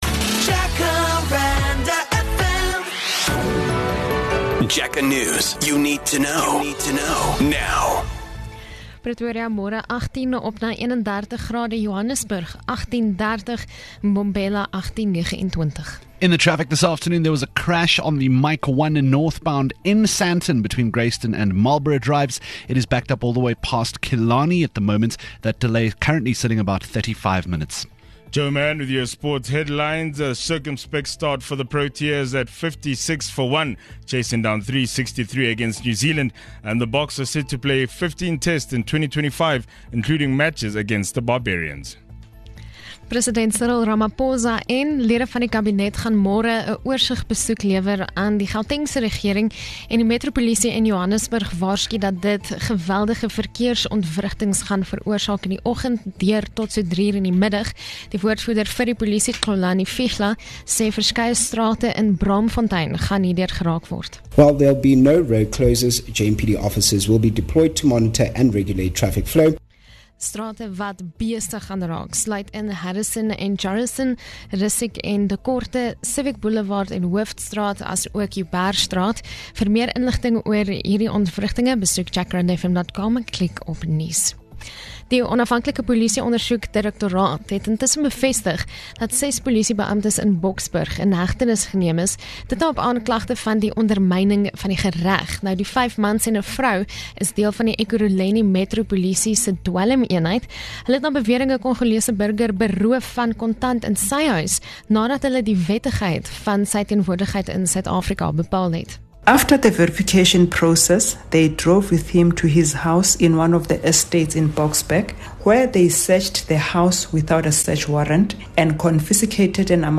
9am News (Afrikaans)